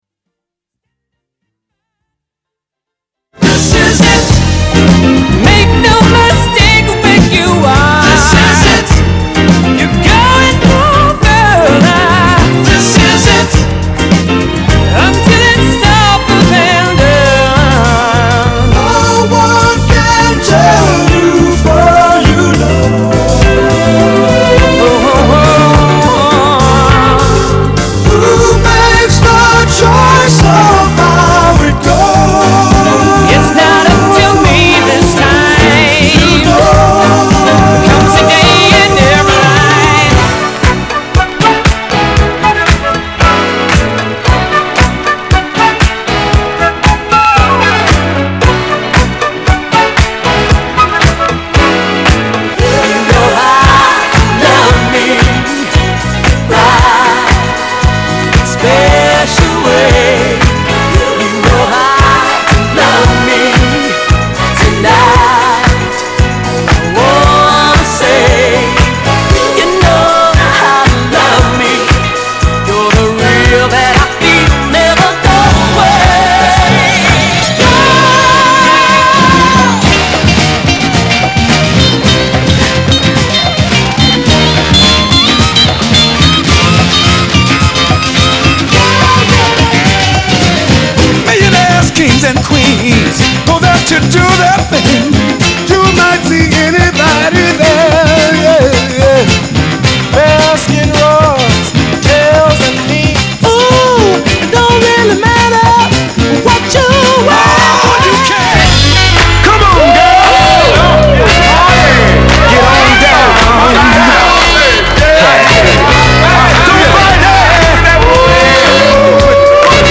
category Disco